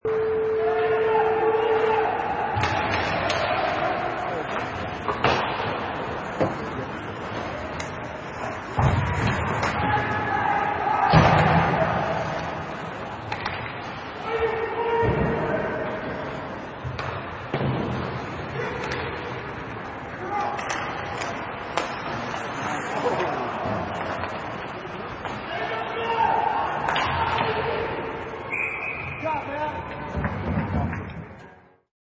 Wav sample: Hockey Match 5
Various sounds of a hockey match
Product Info: 48k 24bit Stereo
Category: Sports / Hockey
Try preview above (pink tone added for copyright).
Hockey_Match_5.mp3